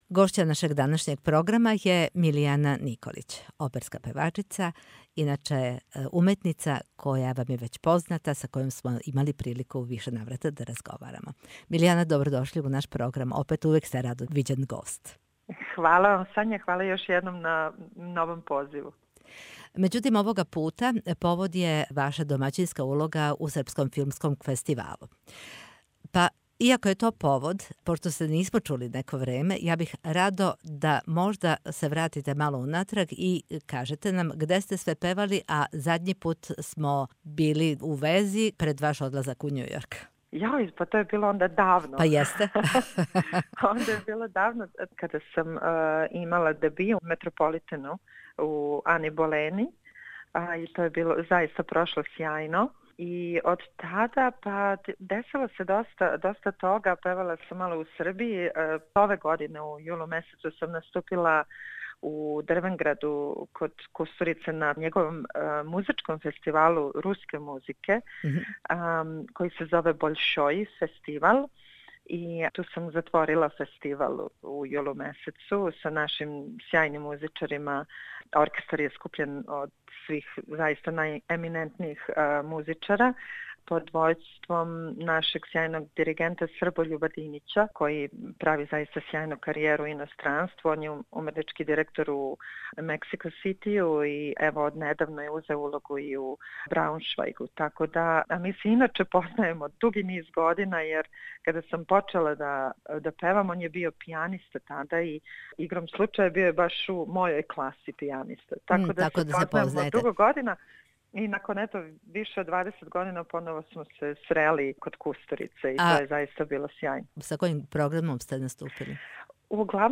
milijana_nikolic_intervju_ok.mp3